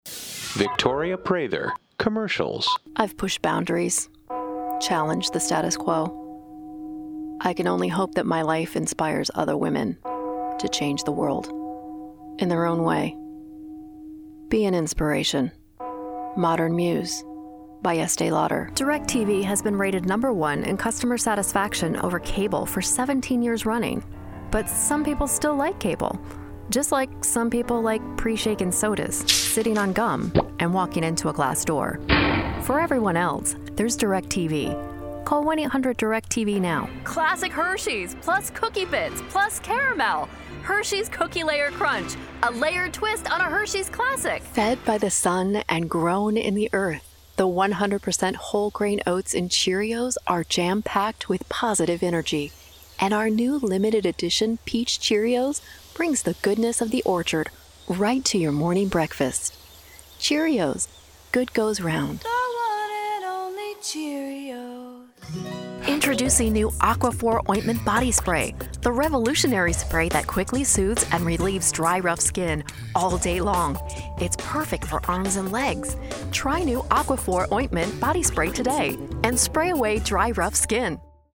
Commercial: